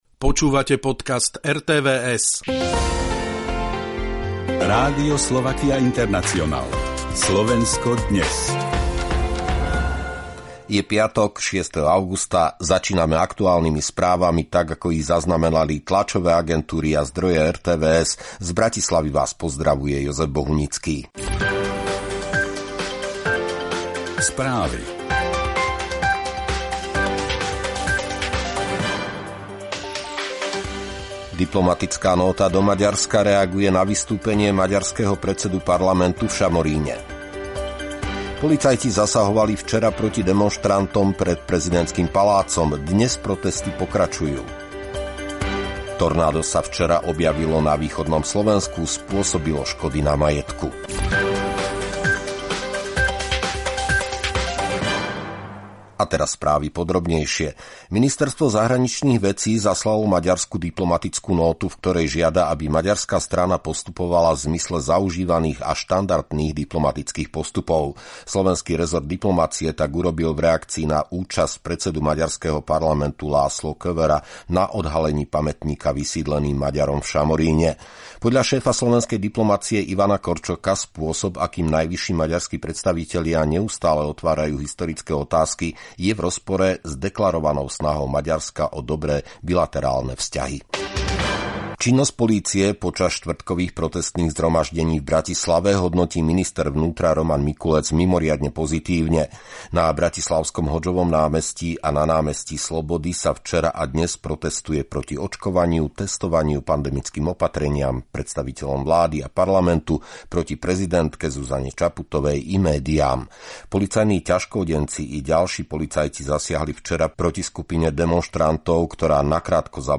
Reportáž: Prelet balónom ponad Kriváň.